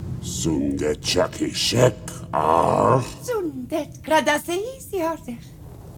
Terak and Charal converse in Sanyassan